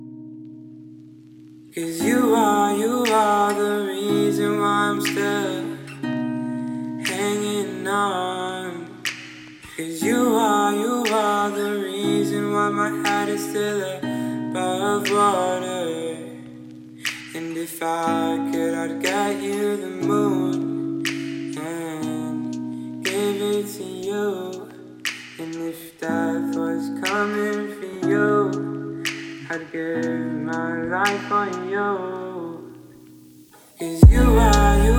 Жанр: Поп / Инди